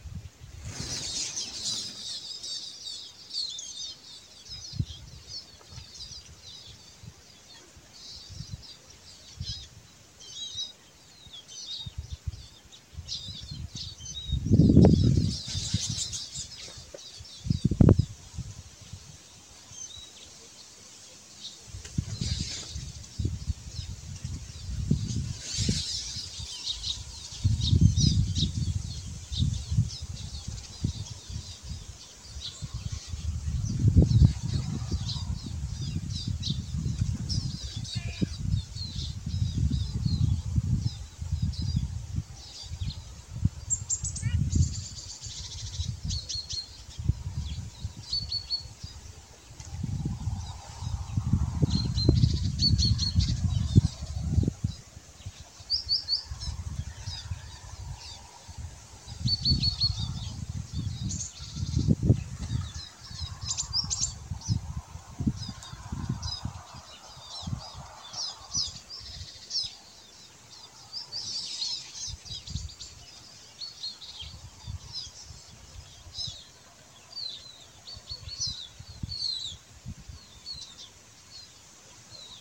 Data resource Xeno-canto - Bird sounds from around the world